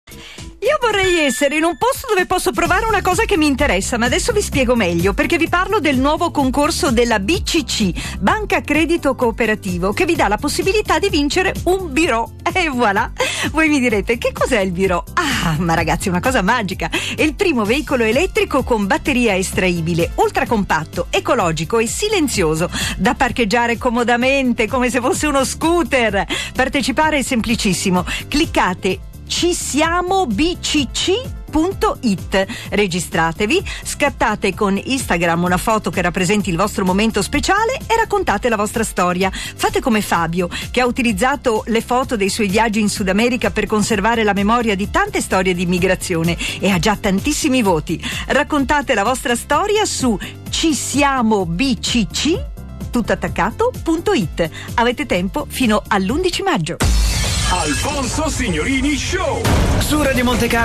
Radio Ads